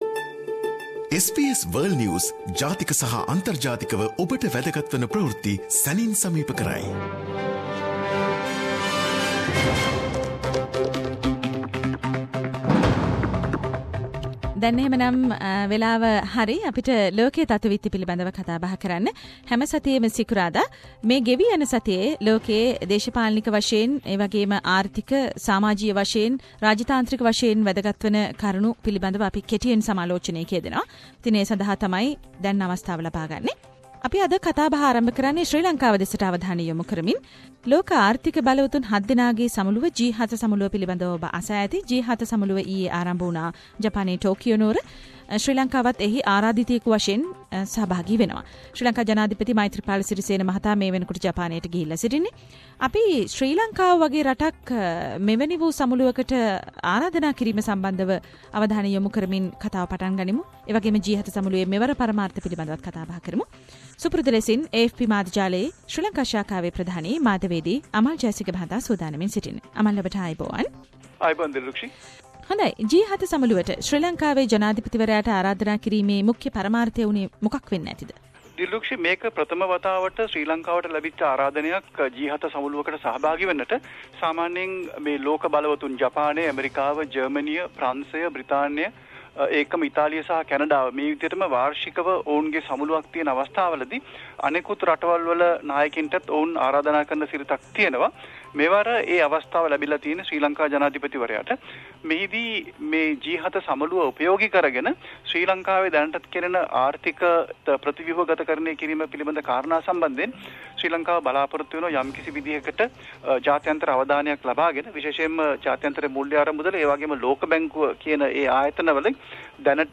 SBS Sinhalese weekly world news wrap